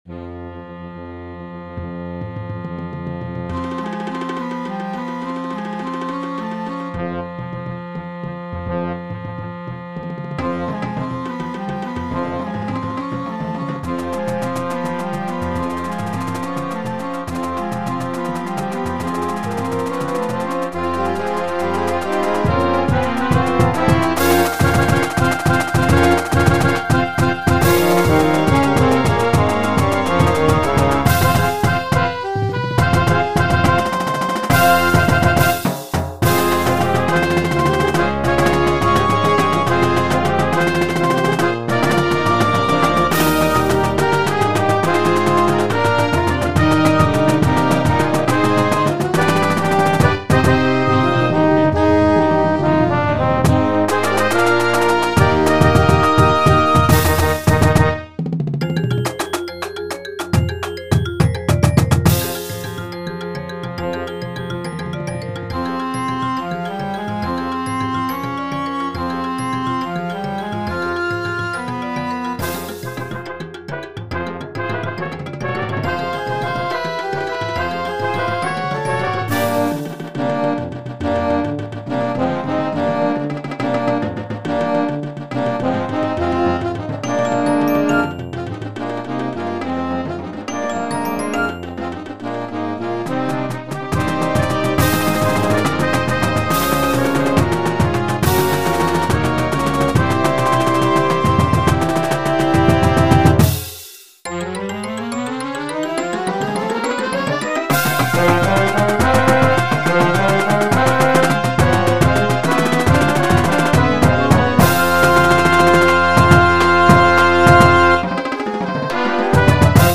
jazz style Marching Band Show about chocolate